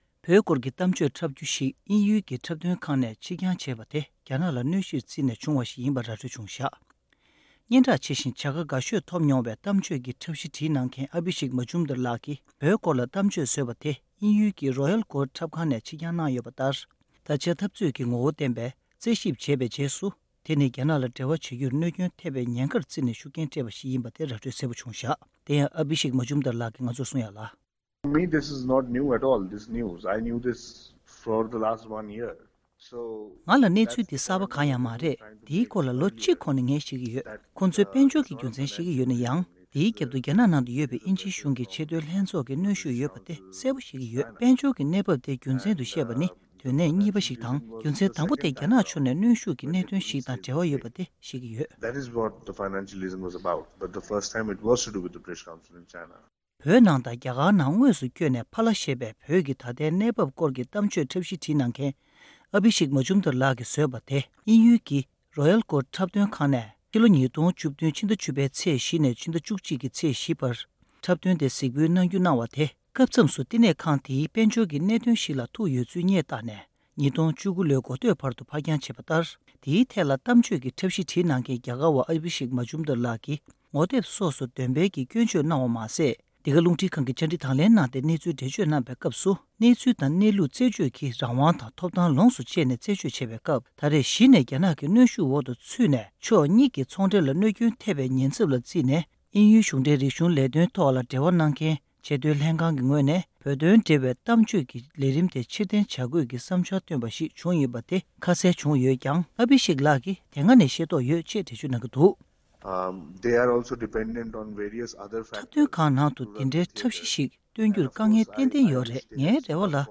གསར་འགྱུར་དཔྱད་ཞིབ